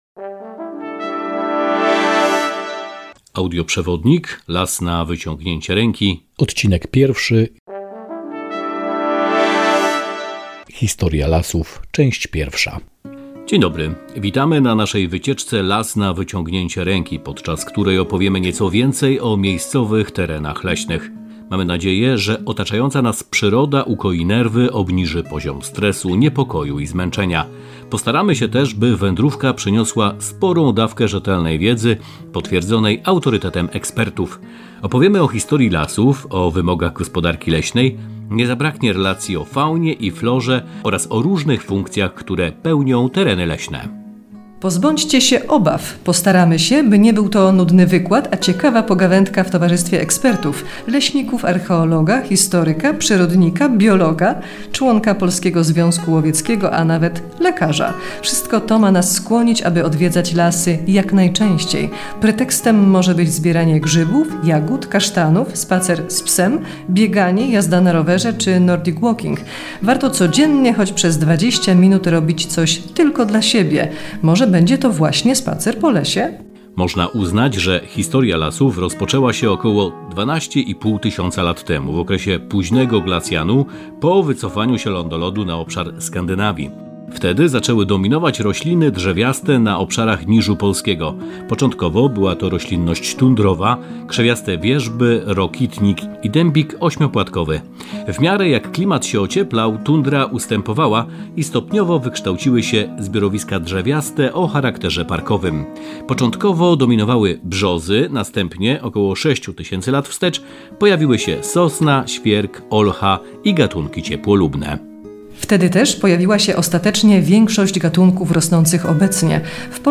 Tablica 1 Drzewa - AUDIOPRZEWODNIK Nadleśnictwo Brzesko
Audioprzewodnik w formie żywych rozmów i opowieści, dzięki bogactwu treści i języka jest źródłem wiedzy i wielu ciekawostek dedykowanych dla osób w różnym wieku i poziomie wiedzy.